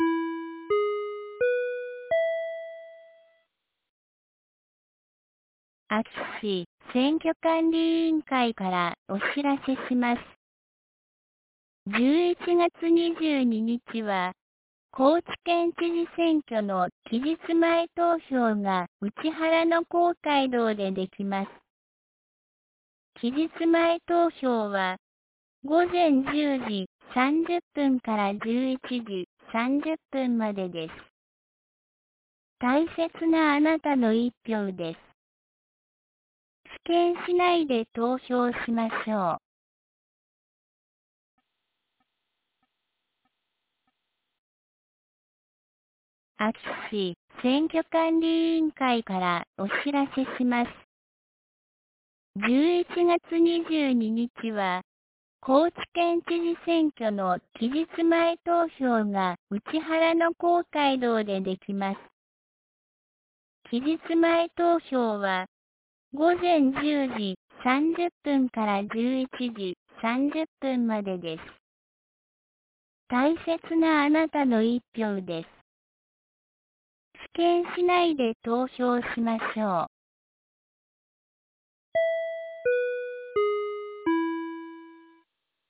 2023年11月20日 09時01分に、安芸市より井ノ口へ放送がありました。